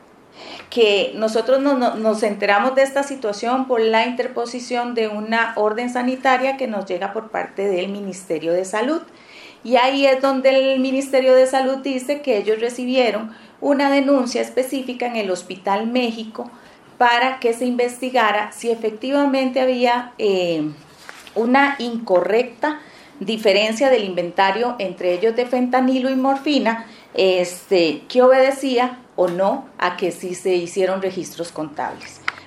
Mónica Taylor, presidenta ejecutiva de la Caja Costarricense de Seguro Social (CCSS), emitió declaraciones imprecisas durante una conferencia de prensa este jueves respecto de las discrepancias en los inventarios de fentanilo, ketamina, morfina y otros estupefacientes del Hospital México.